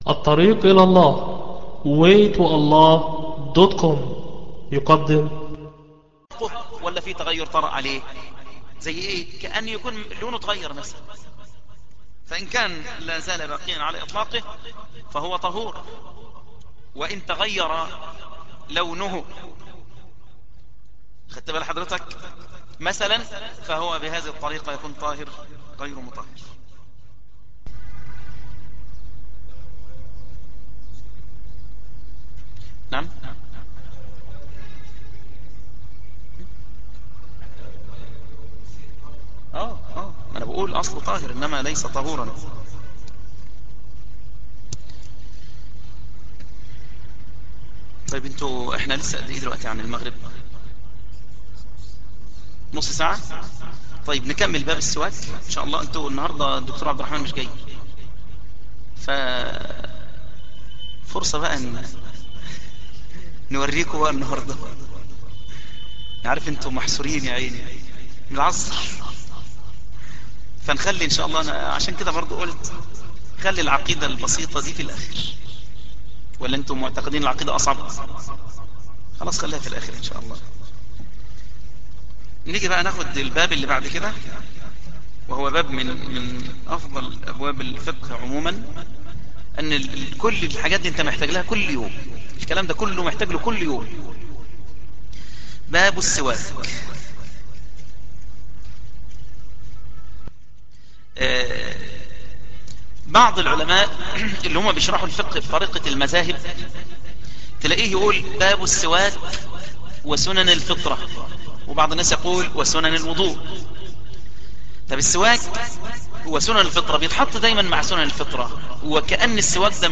فقه الطهاره الدرس الثالث